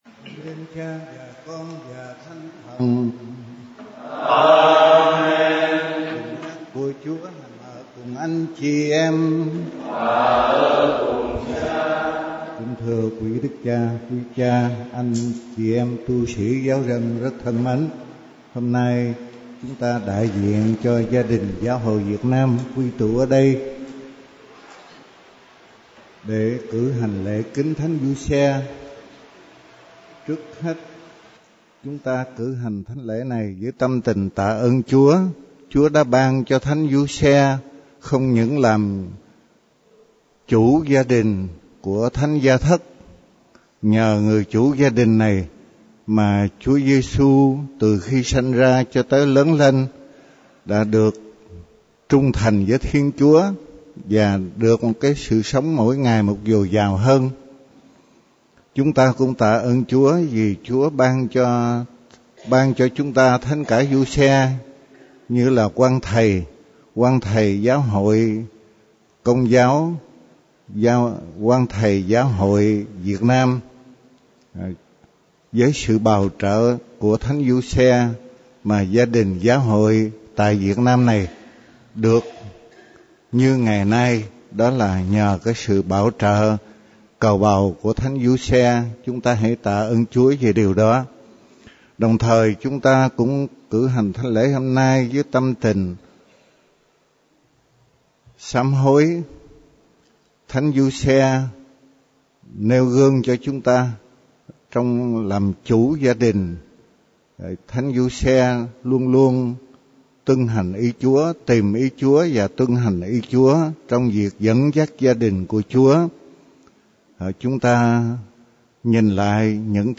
Nhập Lễ sáng 23.11 - Đại Hội Dân Chúa 2010 (Audio)